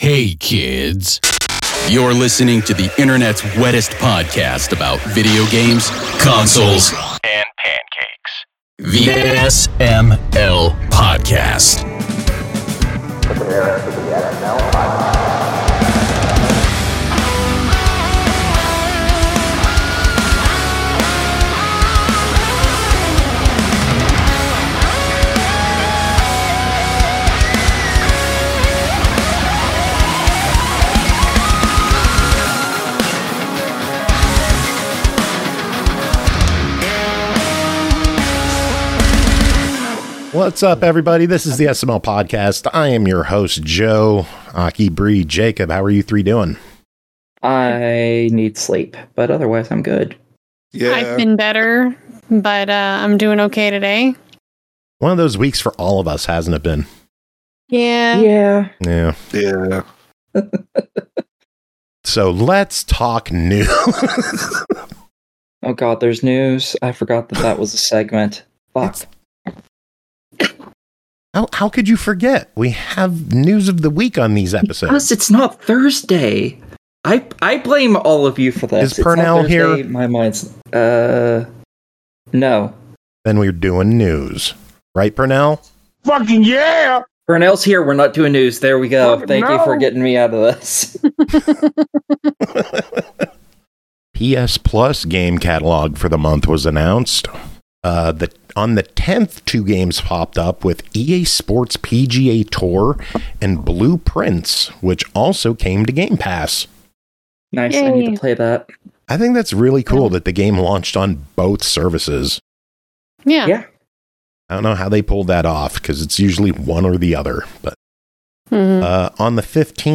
That’s right, it happened just hours before the show and I’m using a cell phone recording.